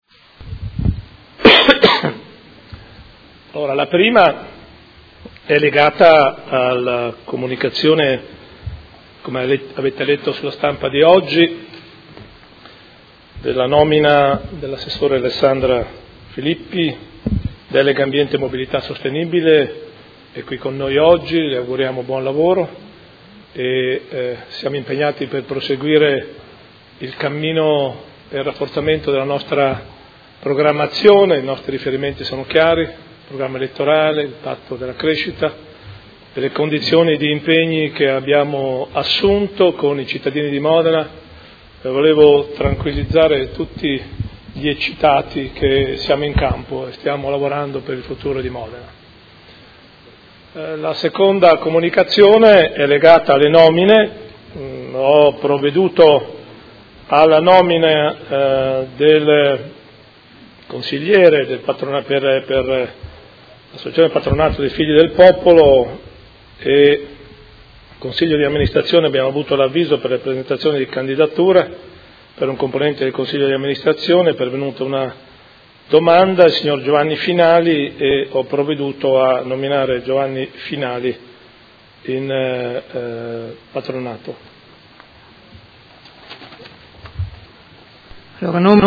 Seduta del 19/04/2018.
Sindaco